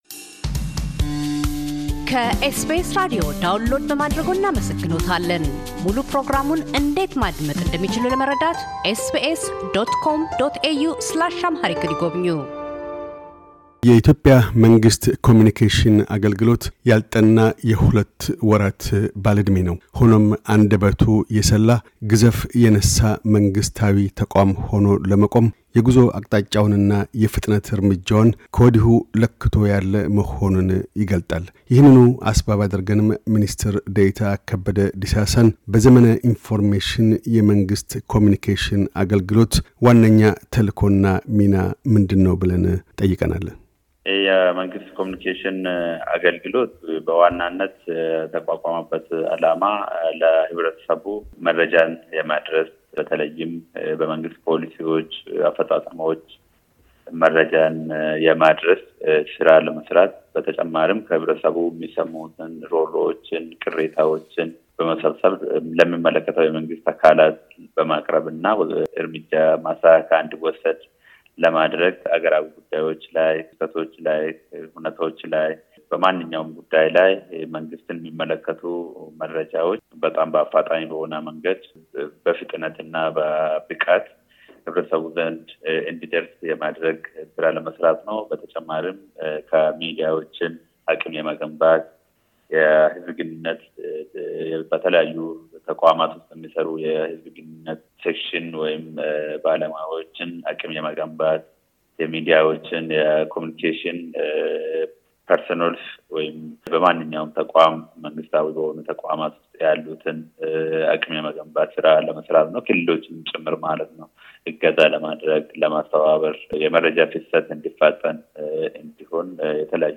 ከበደ ዲሳሳ - የመንግሥት ኮሙዩኒኬሽን አገልግሎት ሚኒስትር ደኤታ፤ ከተመሠረተ ሁለት ወራትን ስላስቆጠረው የኮሙኒኬሽን አገልግሎት እንቅስቃሴዎችና ትልሞችን አስመልክተው ይናገራሉ።